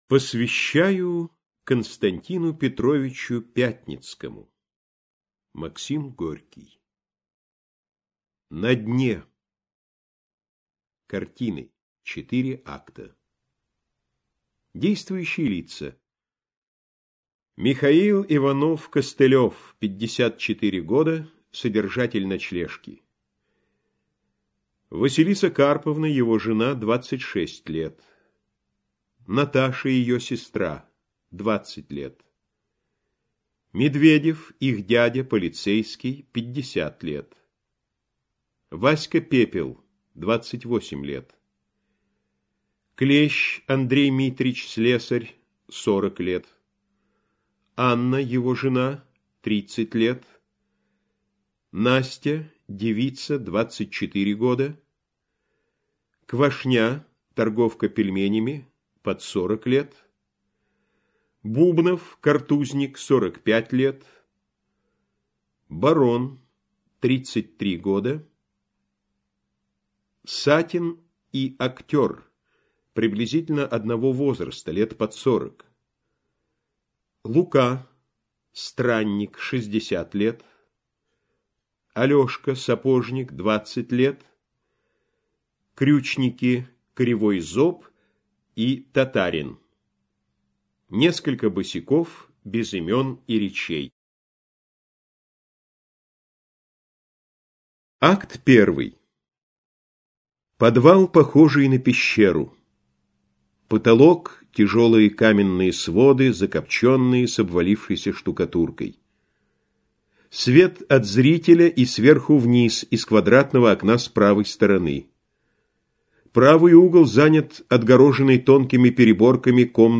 Аудиокнига На дне - купить, скачать и слушать онлайн | КнигоПоиск